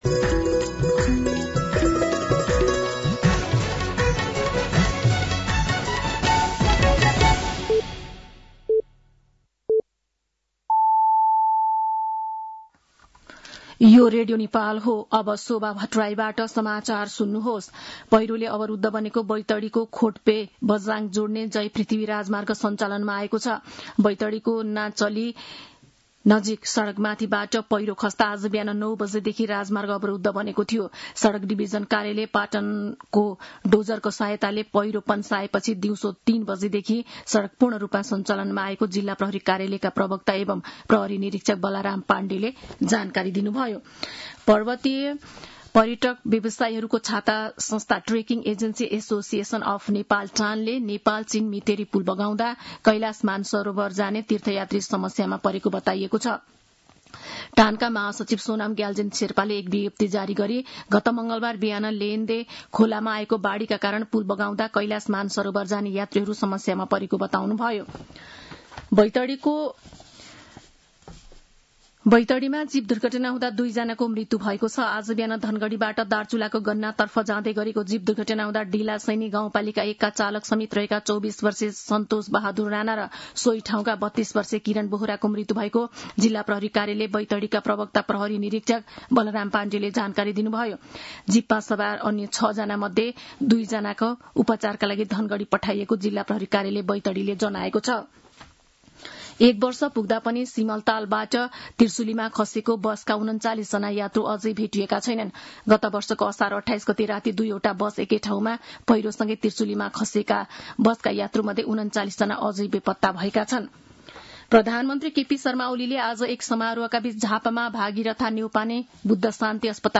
साँझ ५ बजेको नेपाली समाचार : २८ असार , २०८२